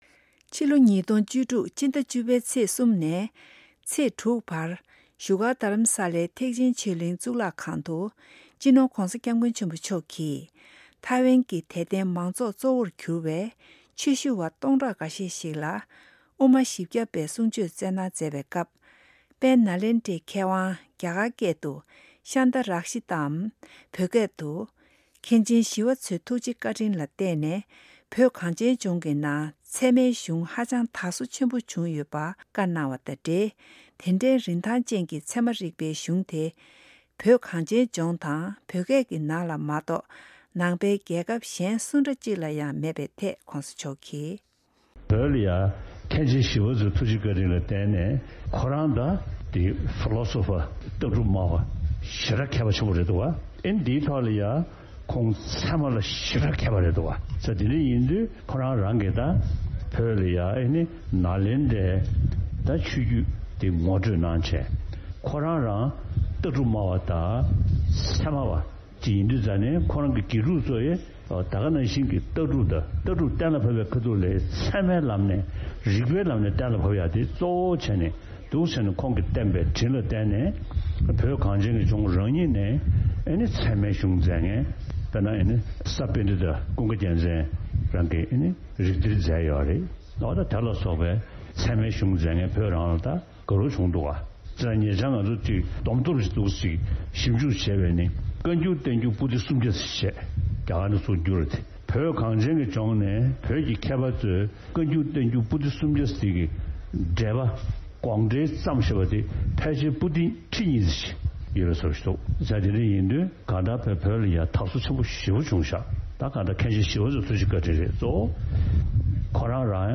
ཉེ་དུས་ཕྱི་ལོ་༢༠༡༦ཕྱི་ཟླ་༡༠པའི་ཚེས་༣ནས་ཚེས་༦བར་བཞུགས་སྒར་རྡ་རམ་སའི་ཐེག་ཆེན་ཆོས་གླིང་གཙུག་ལག་ཁང་དུ་༧སྤྱི་ནོར་༧གོང་ས་༧སྐྱབས་མགོན་ཆེན་པོ་མཆོག་གིས་ཐའི་ཝེན་གྱི་ཆོས་དད་པ་གཙོ་བོར་གྱུར་བའི་དད་ལྡན་ཆོས་ཞུ་བ་སྟོང་ཕྲག་འགའ་ཤས་ལ་སློབ་དཔོན་འཕགས་པ་ལྷས་མཛད་པའི་དབུ་མ་བཞི་རྒྱ་པའི་བཤད་ལུང་གནང་བ་ཞིག་གསན་གྱི་རེད།